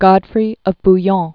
(gŏdfrē; b-yôɴ) 1061?-1100.